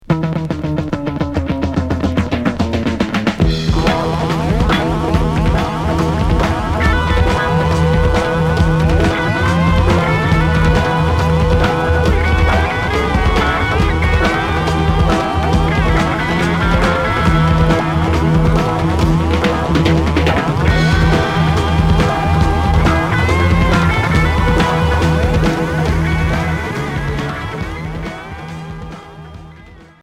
Heavy rock pop psyché Premier 45t retour à l'accueil